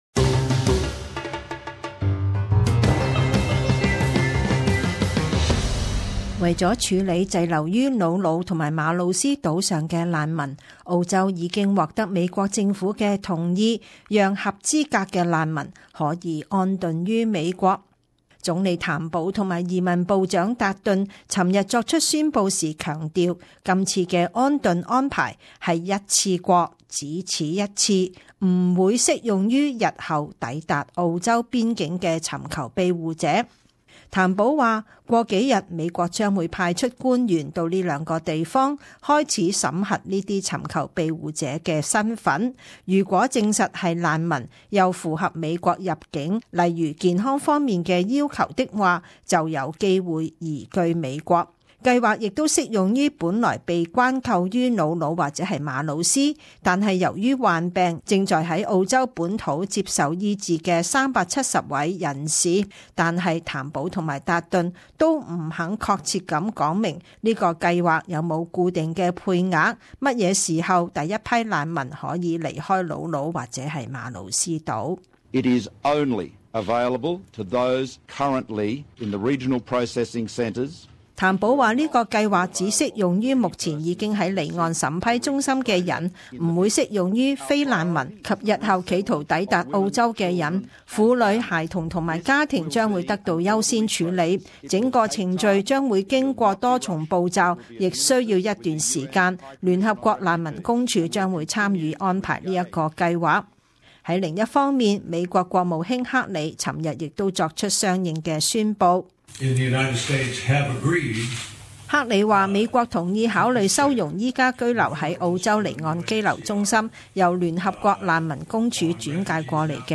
時事報導： 澳美達成難民移居美國協議